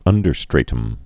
(ŭndər-strātəm, -strătəm)